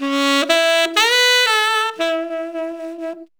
NGM_SaxMelody.ogg